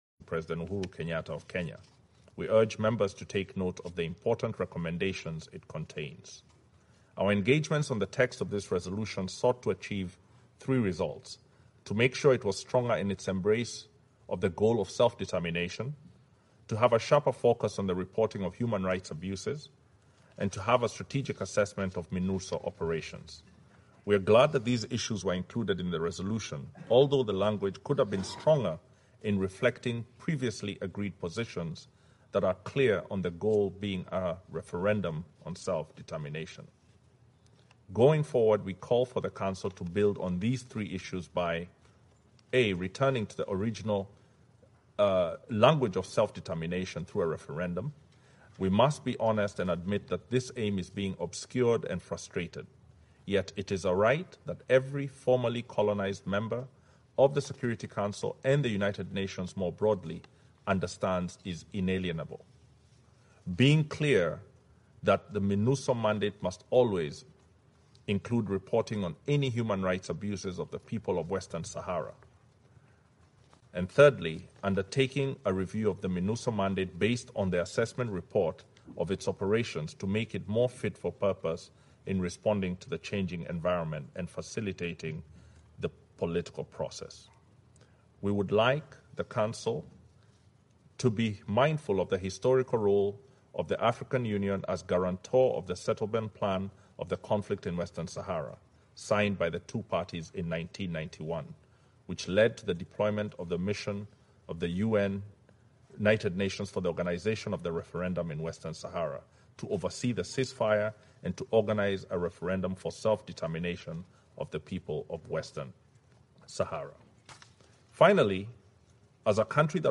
مداخلة مندوب كينيا خلال جلسة تصويت مجلس الأمن الدولي حول الصحراء الغربية